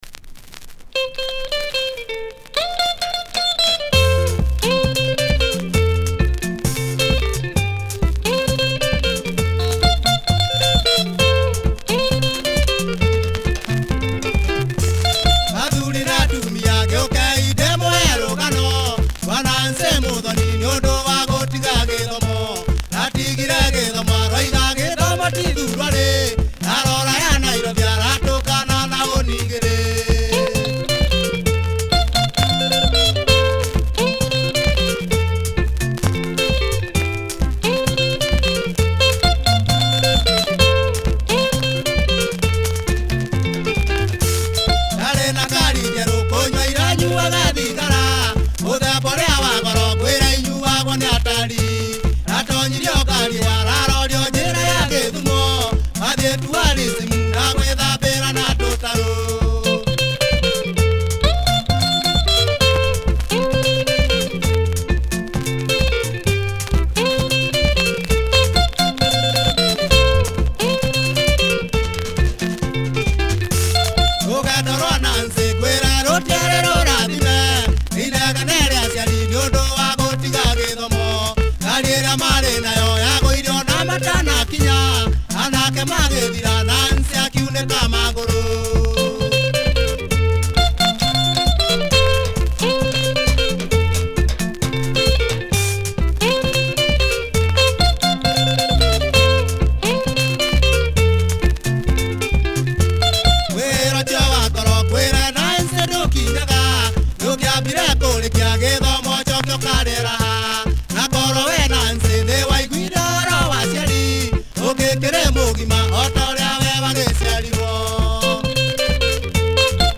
Catchy Kikuyu Benga